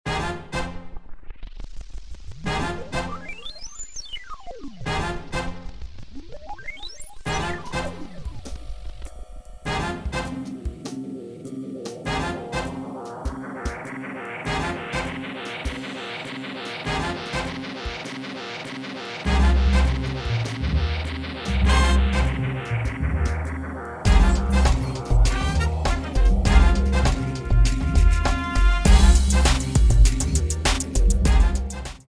10 Music tracks in various dance styles.
Warm-up, Cool Down, Modern, Jazz, Hip Hop